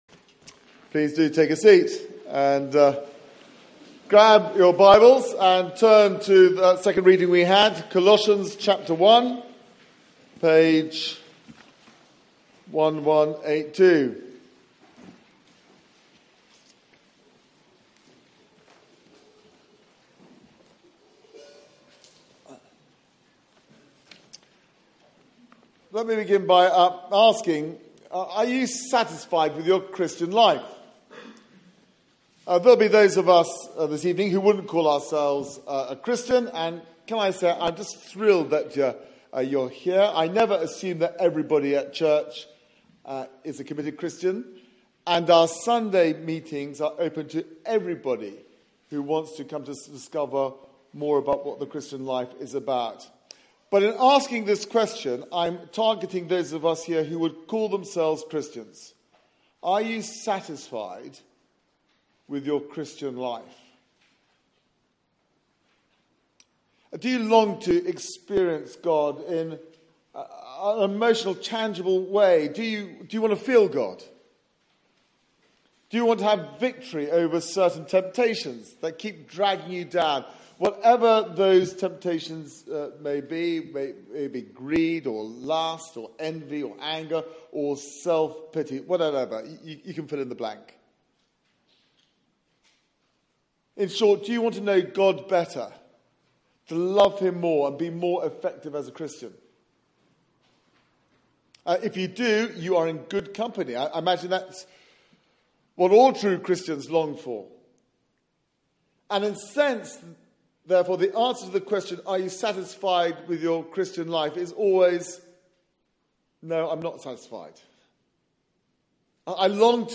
Media for 6:30pm Service on Sun 25th Sep 2016
Series: Rooted in Christ Theme: Being part of the biggest thing in the world Sermon